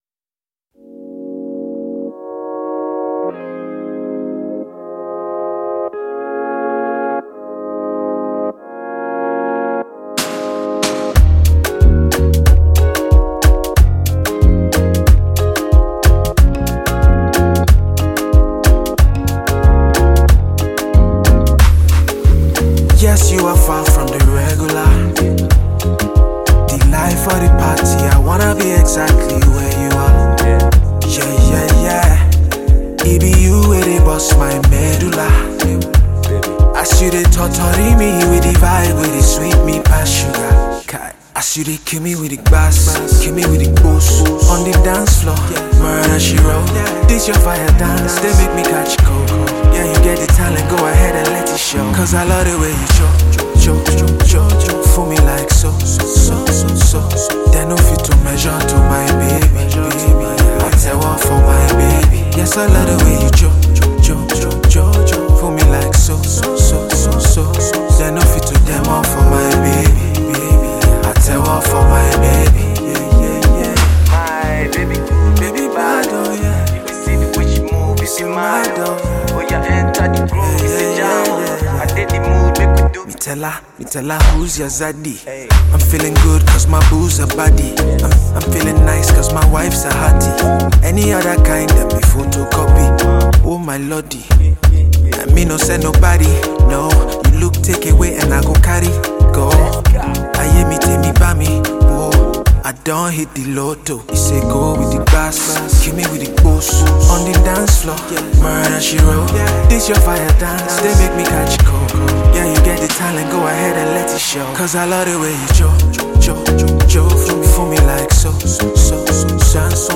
ballad single